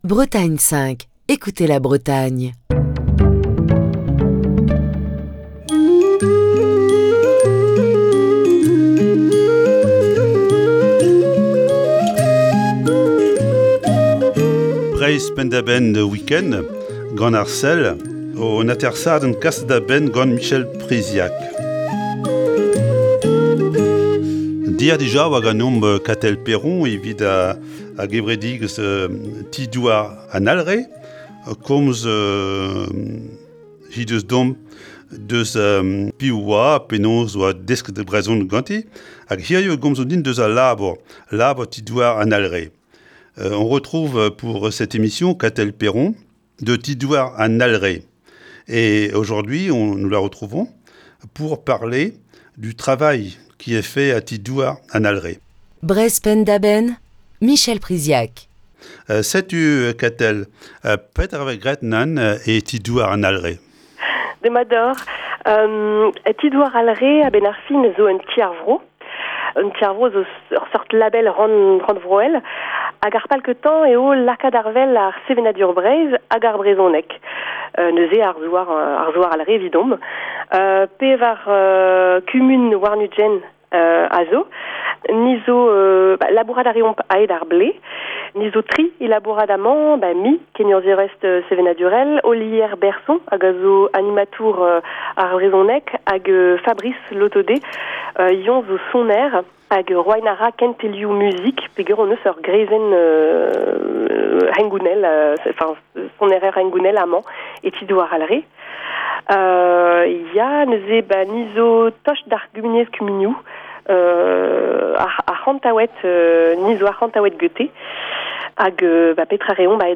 Voici ce dimanche, la seconde partie de cet entretien.